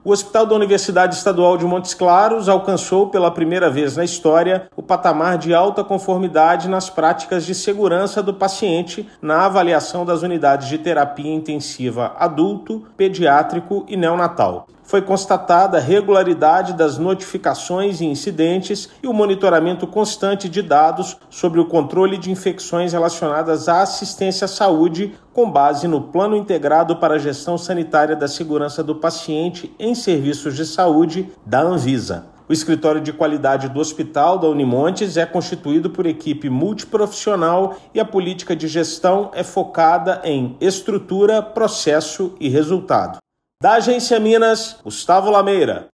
O Hospital Universitário da Universidade Estadual de Montes Claros (HU-Unimontes) alcançou, pela primeira vez na história, o patamar de alta conformidade nas práticas de segurança do paciente, na regularidade das notificações e incidentes, e também no monitoramento constante dos dados envolvendo o controle de infecções relacionadas à assistência à saúde.O objetivo é avaliar setores de Unidades de Terapia Intensiva (UTI) adulto, pediátrico e neonatal. Ouça matéria de rádio.